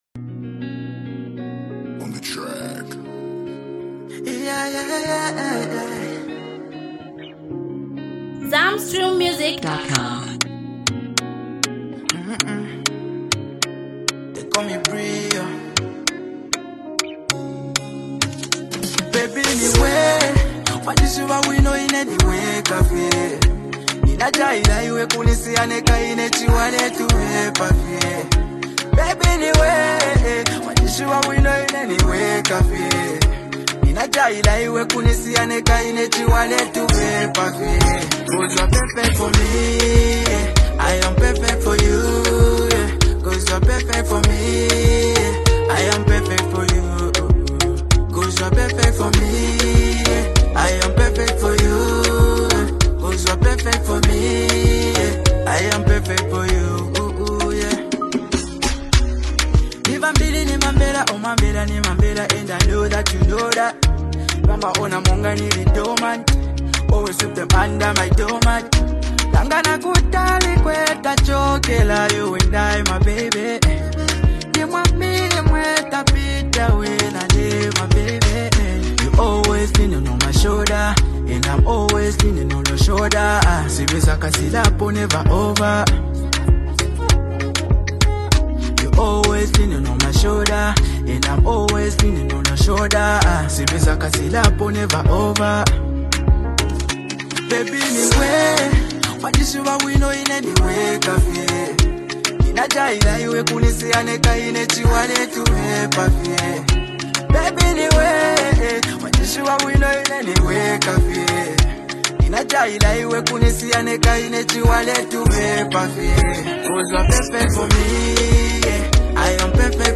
a heart-melting love anthem